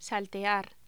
Título Locución: Saltear
Sonidos: Voz humana